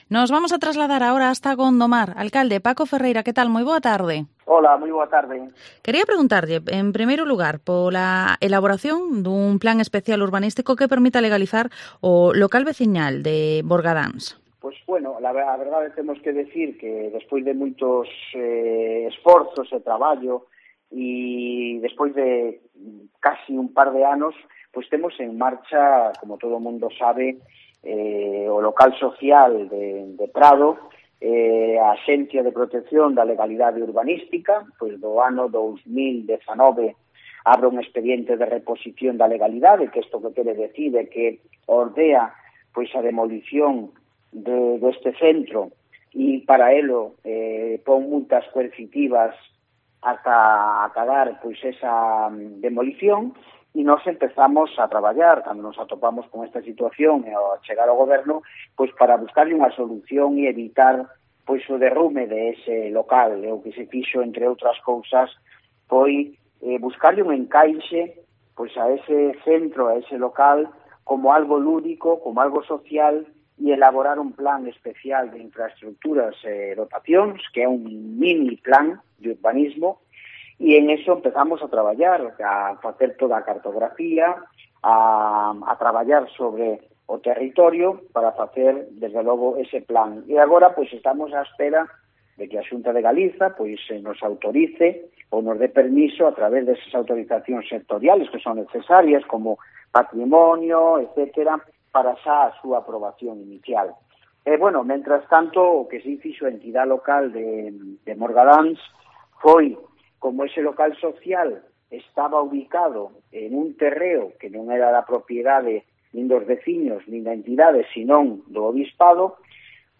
Entrevista Alcalde de Gondomar, Paco Ferreira